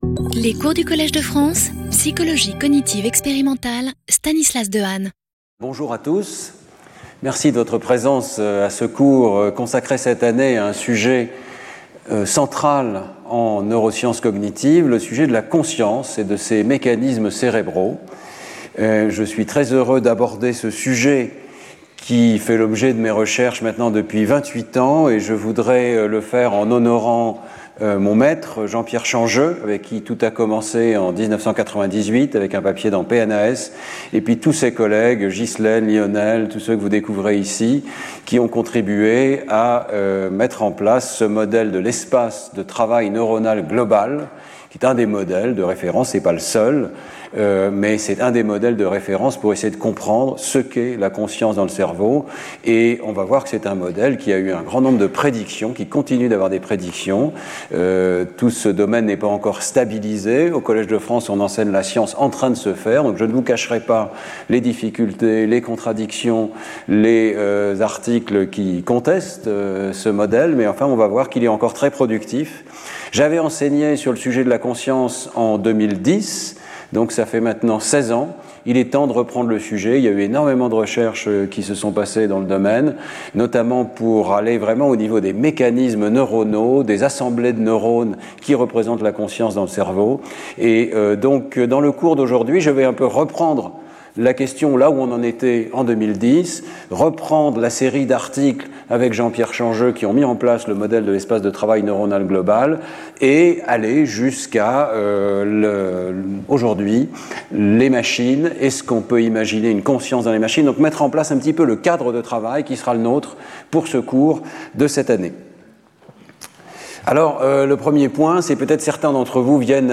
Intervenant(s) Stanislas Dehaene Professeur du Collège de France
Cours